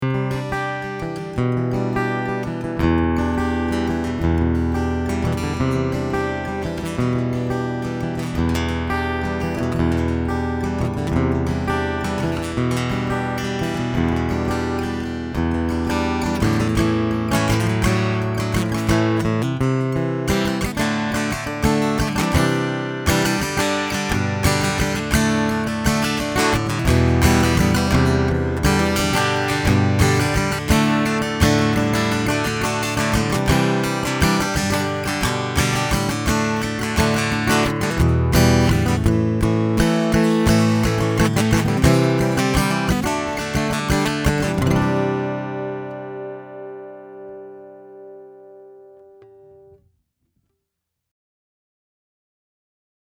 All of the clips are with the guitar plugged directly into my pre-amp going into my DAW.
Reference Tone (raw, image mix turned all the way off)
The reference track was quacky and honky.